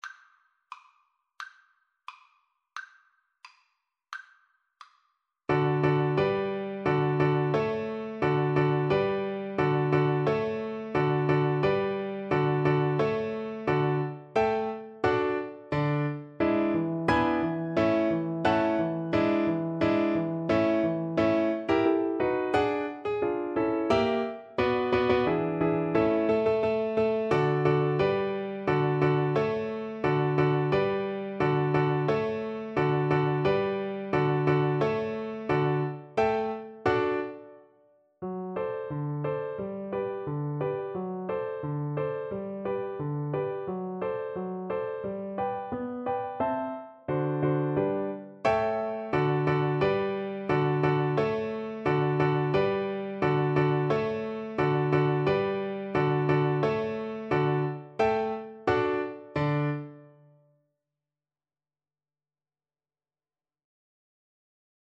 D major (Sounding Pitch) (View more D major Music for Violin )
2/4 (View more 2/4 Music)
~ = 88 Stately =c.88
Classical (View more Classical Violin Music)